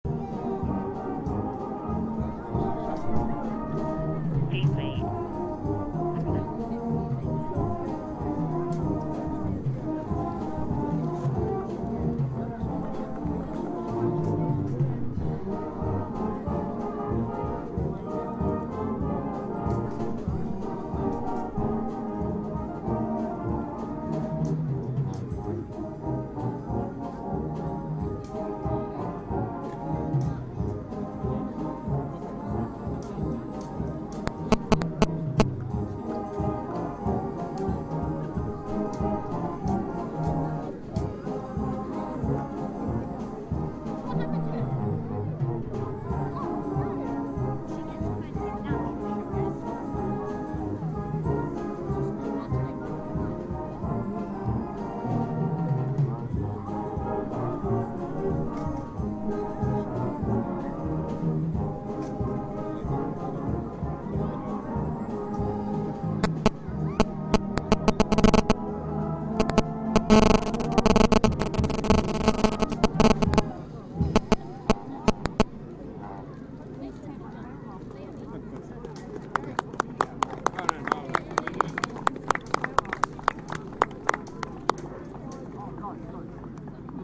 chase Brass on Monday afternoon. Anyone know what the tune is, please?